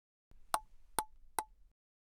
A new experience of soft feel and quiet sound — from the next generation of rubber
As a result, Zyre 03 offers a softer feel compared to conventional rubbers with the same sponge hardness, along with a quieter hitting sound — creating a pleasant hitting experience unique to the new generation of rubber.